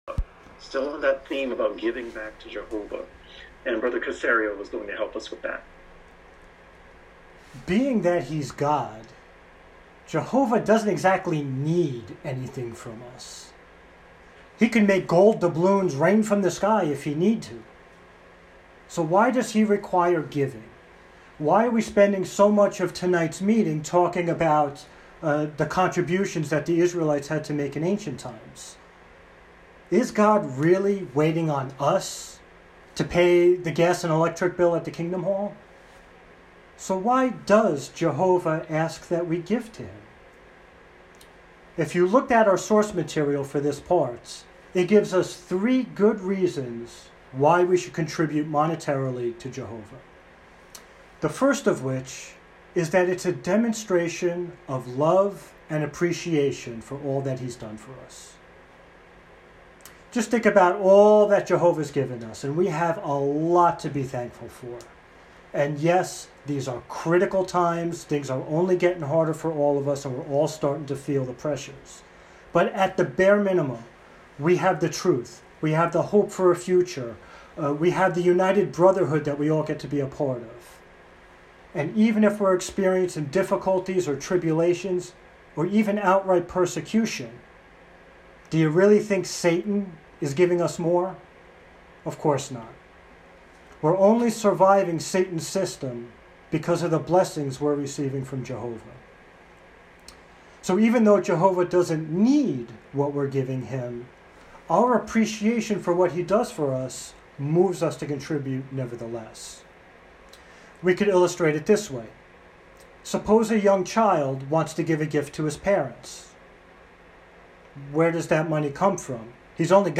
Given at home over Zoom during the Covid years
Talk: (5 min.) w18.01 18 ¶4-6—Theme: Why Do We Give to Jehovah? (th study 20)